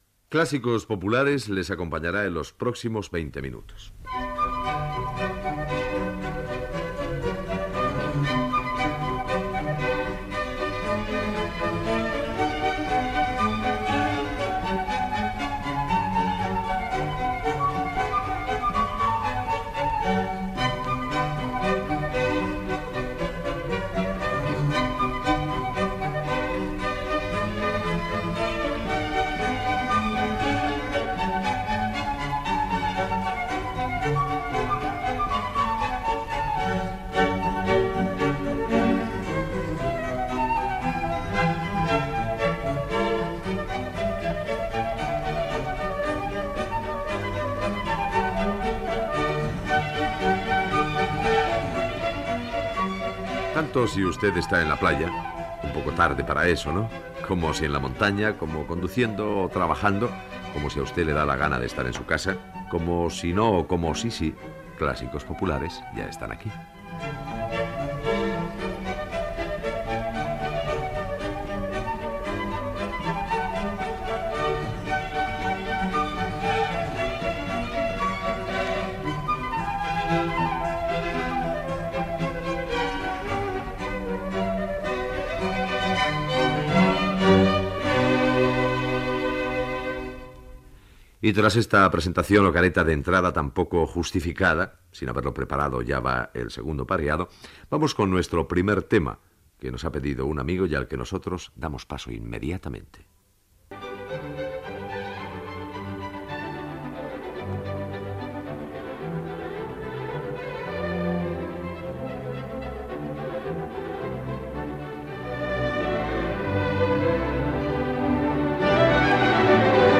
Presentació del programa amb la sintonia d'entrada i de dos temes musicals
Musical